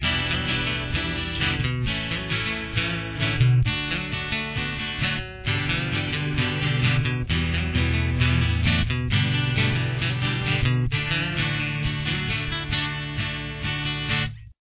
• Key Of: D
• Solo: D Pentatonic Major (with a few alternates)
Guitar Solo 1